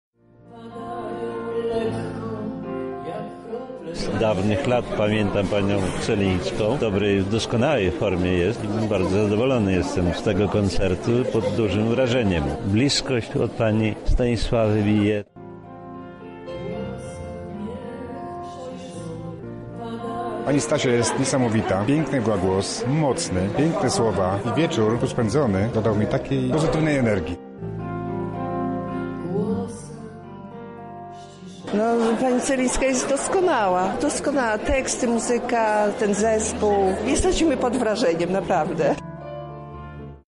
Miłośnicy jej twórczości podzielili się wrażeniami: